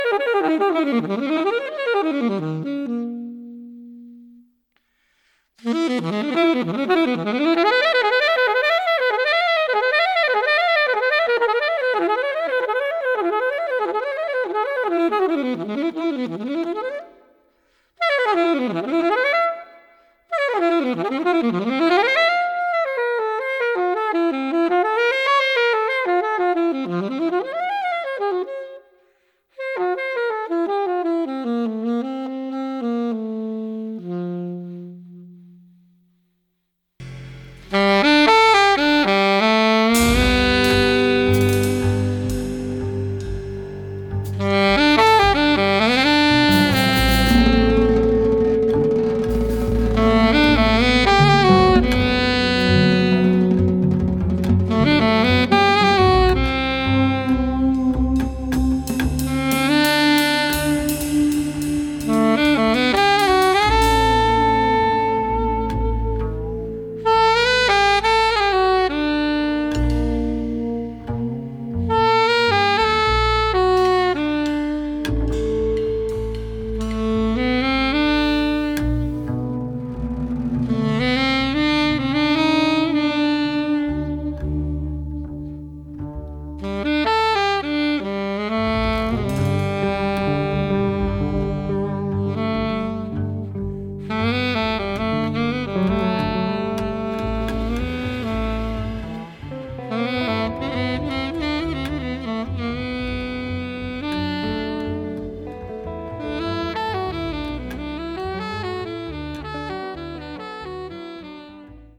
exquisite melody